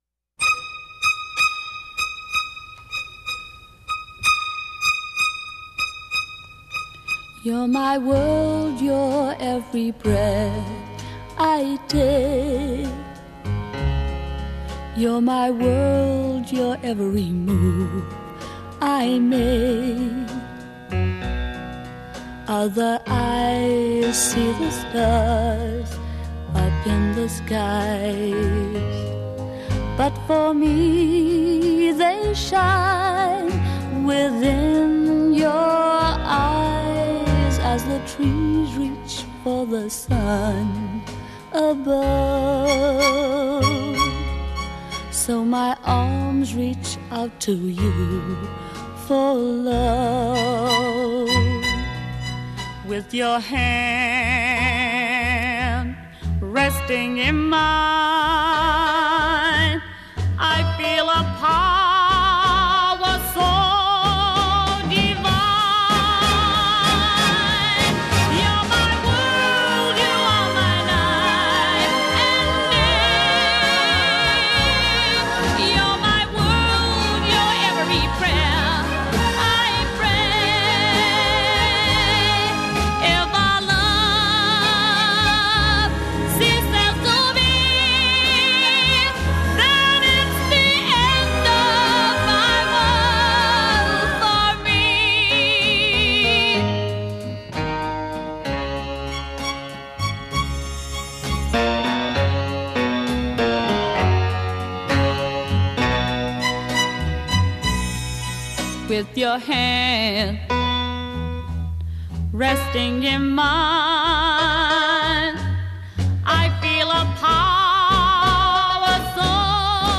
guitar
percussion
Verse     vocal starts and stays in her lower register a